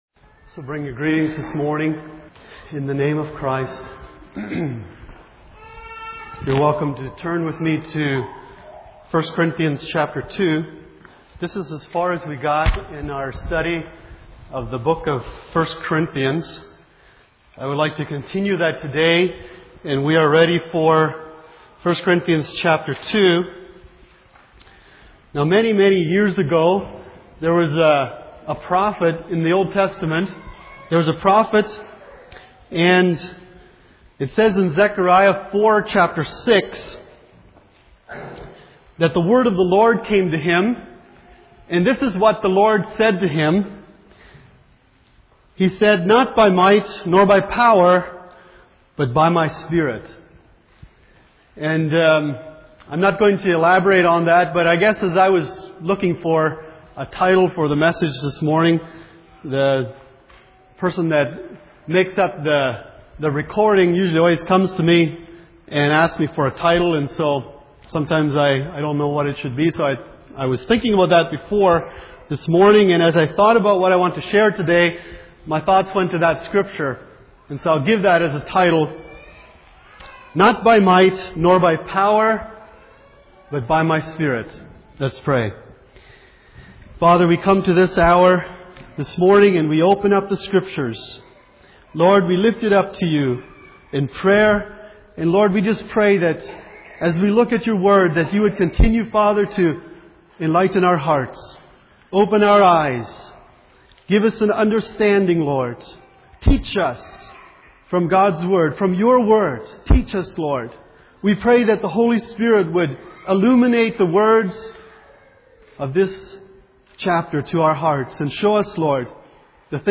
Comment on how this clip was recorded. Passage: 1 Corinthians 2:1-16 Service Type: Sunday Morning